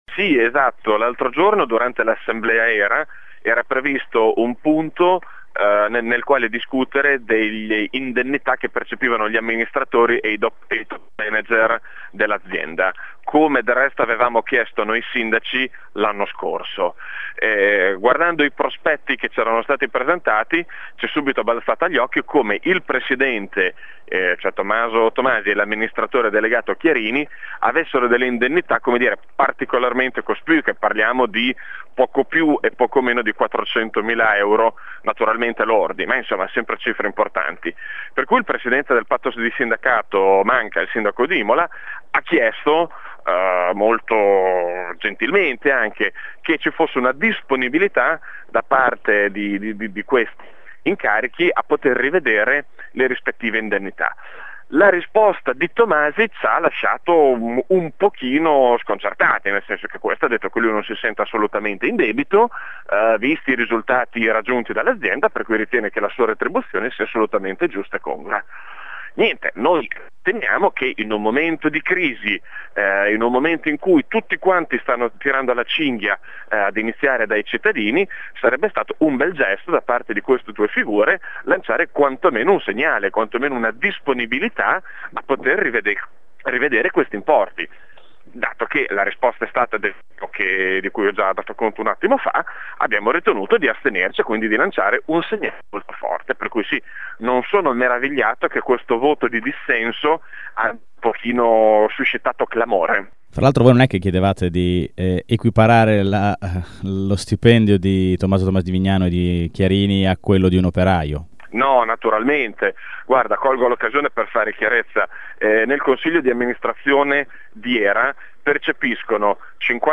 Non che i sindaci chiedessero la luna: “Diminuire di un certo x per cento questi numeri qua – dice Lorenzo Minganti, sindaco di Minerbio, ai nostri microfoni – sarebbe stato un bel gesto”.